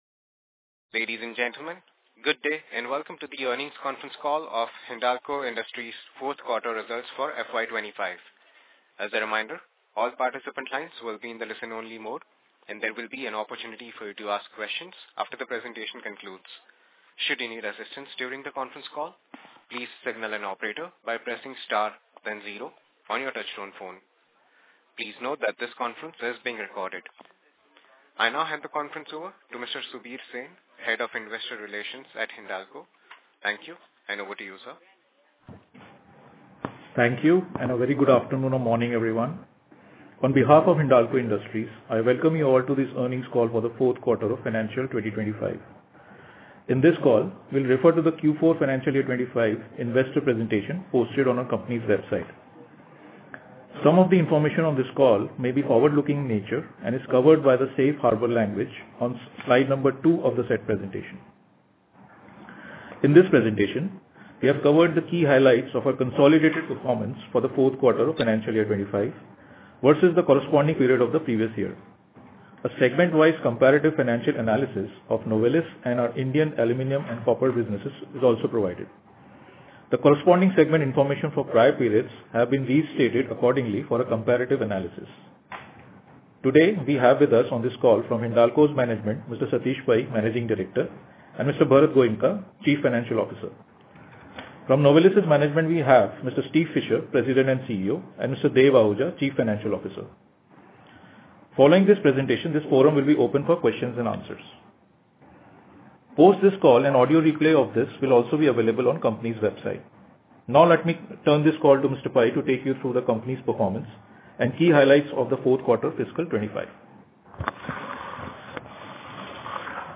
q4-fy25-earnings-call.mp3